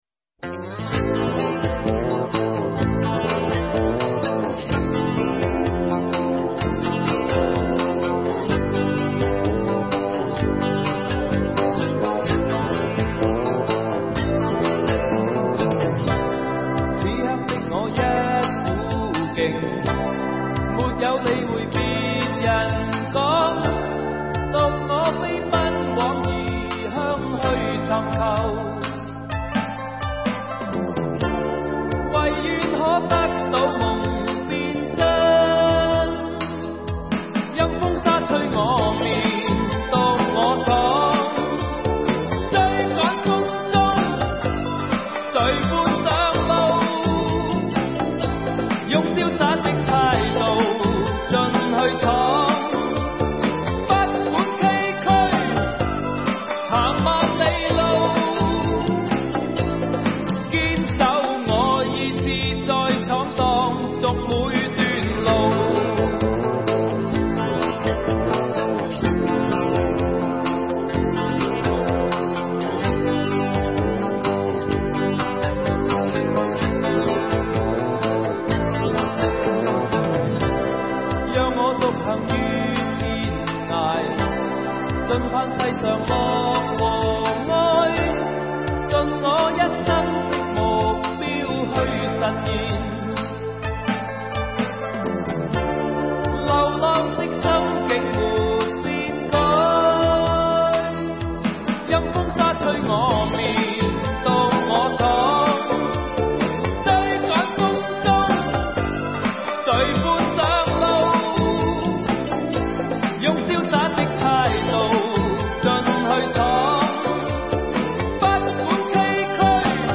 粤语专辑